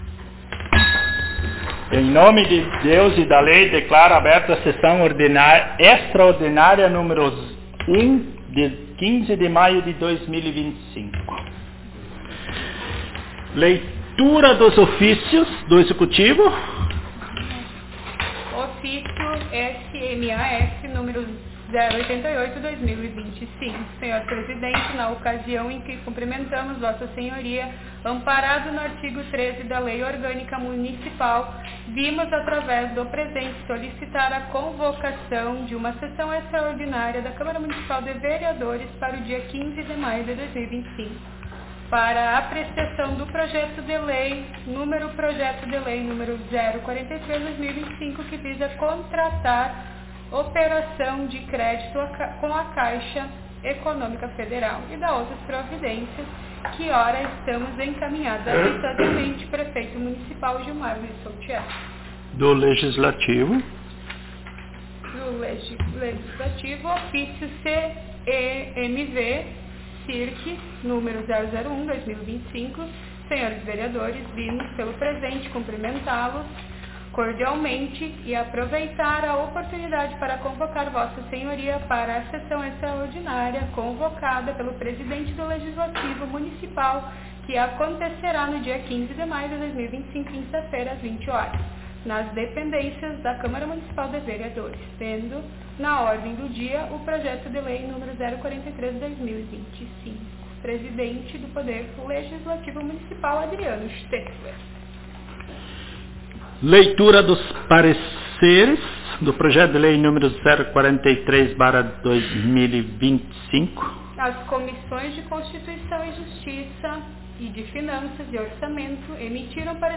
Aos 15 (quinze) dias do mês de maio do ano de 2025 (dois mil e vinte e cinco), na Sala de Sessões da Câmara Municipal de Vereadores de Travesseiro/RS, realizou-se a Primeira Sessão Extraordinária da Legislatura 2025-2028.